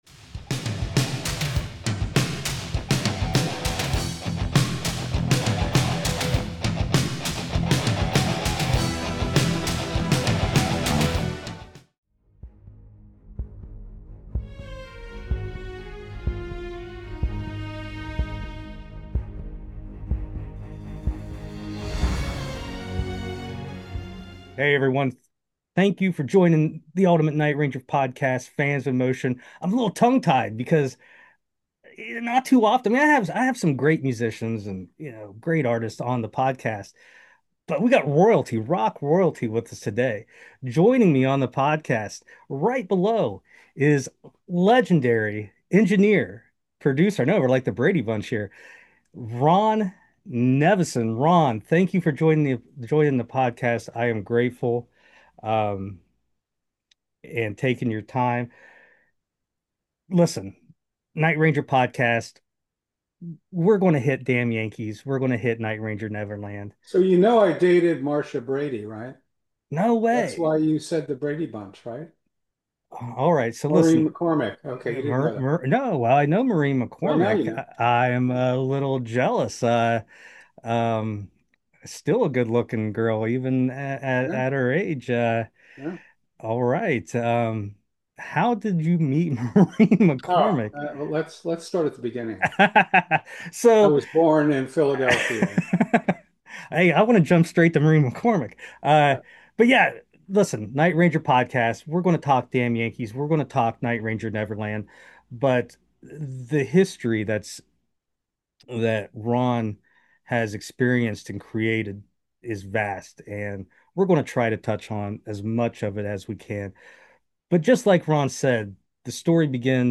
Fans In Motion Ep 179: Interview w/DAMN YANKEES & NIGHT RANGER Producer RON NEVISON Part One!